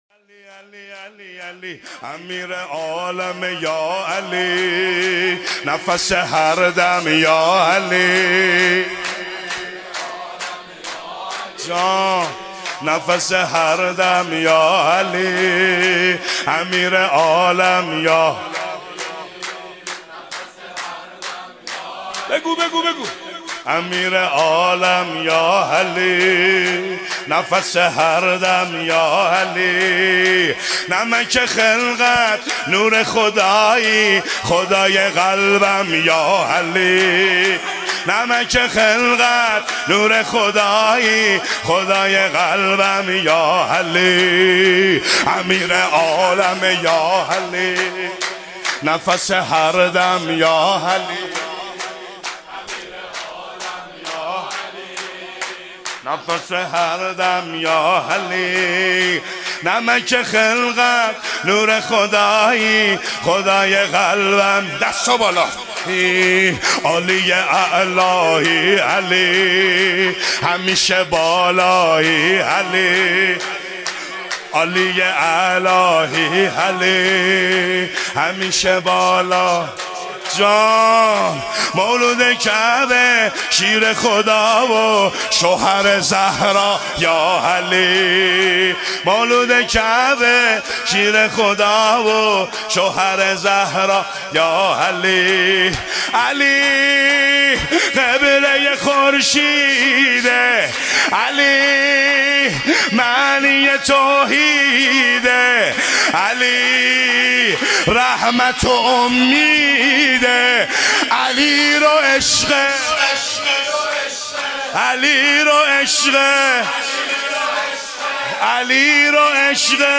هیئت مکتب الزهرا (س)
ولادت امام علی (ع)